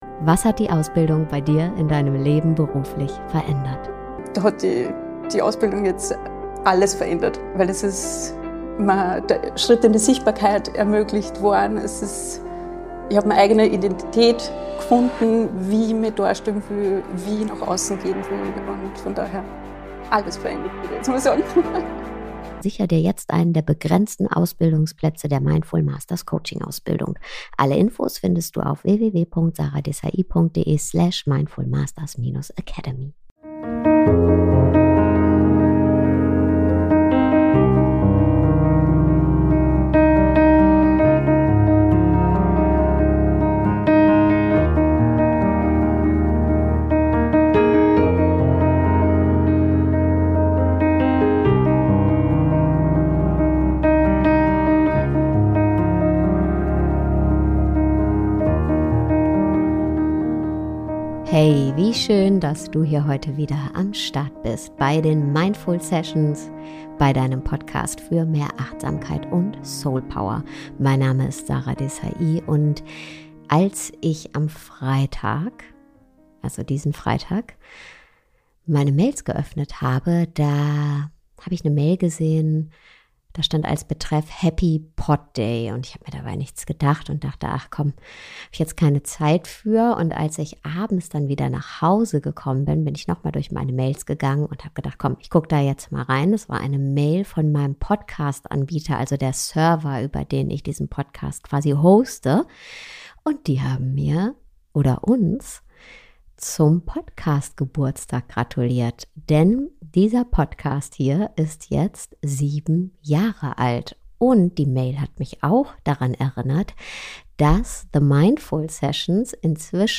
Wie du Freunde fürs Leben findest - Ein Gespräch unter Freundinnen